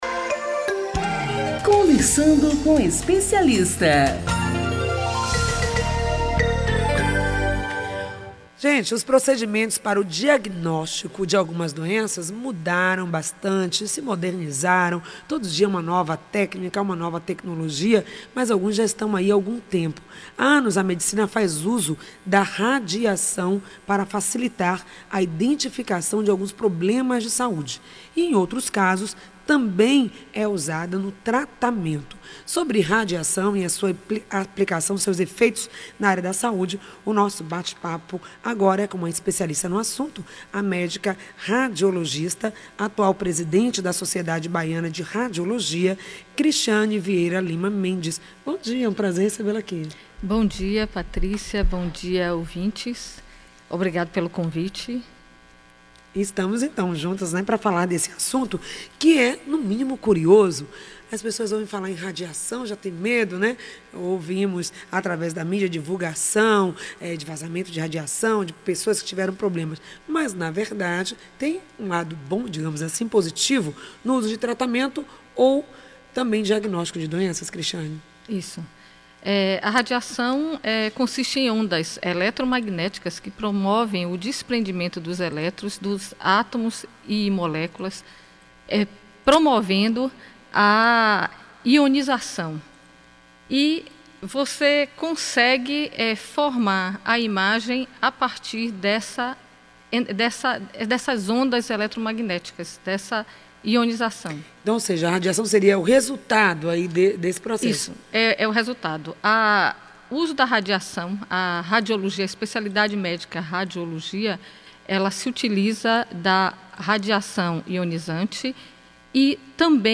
O programa exibido pela Rádio AM 840 (em 30.03.16 das 8 às 9h) abordou assuntos como: profissão do radiologista:o que fazem, como são formados, onde atuam, o que é radiação ionizante e não ionizante, riscos e utilidades do radio x, ultra sonografia. Mitos e verdades sobre o celular, microondas. Efeitos da radiação para a saúde.